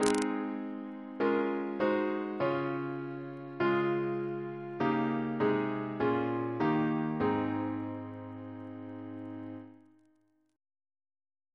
Single chant in A♭ Composer: C. Allan Wickes (1824-1860) Reference psalters: PP/SNCB: 222